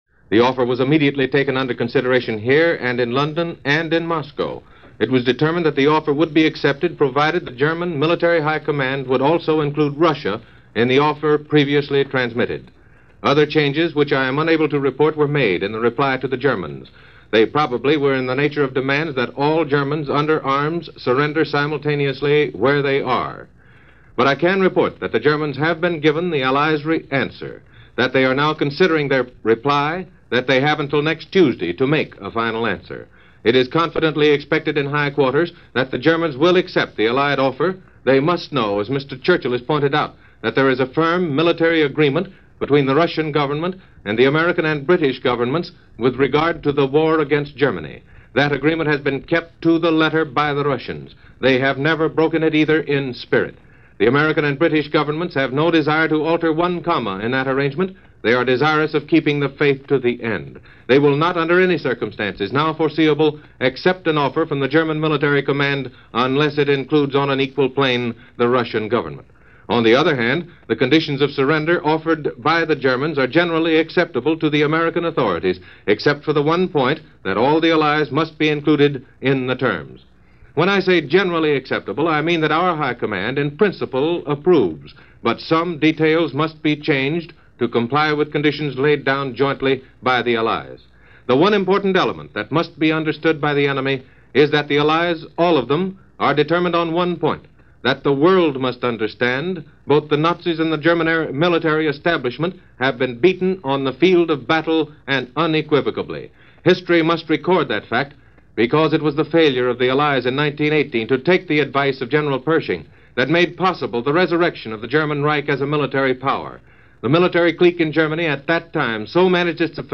The Press lobbing questions at President Truman over VE Day rumors – Harry said “not yet”.
That’s how this day rolled in 1945, as reported over NBC Radio with bulletins and special reports for April 28, 1945.